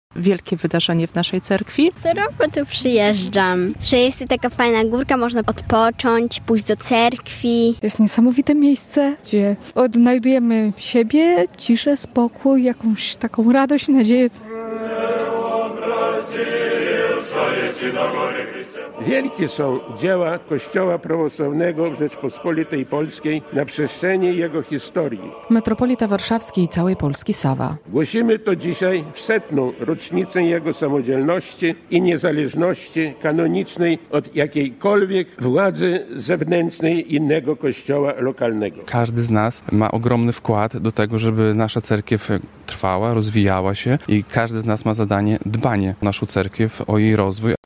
Na Świętej Górze Grabarce zakończyły się dwudniowe uroczystości z okazji święta Przemienienia Pańskiego - relacja